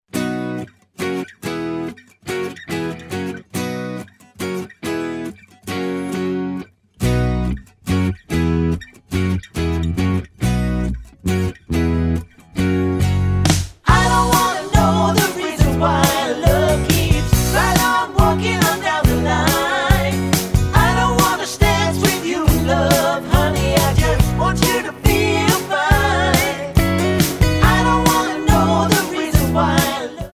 Tonart:B Multifile (kein Sofortdownload.
Die besten Playbacks Instrumentals und Karaoke Versionen .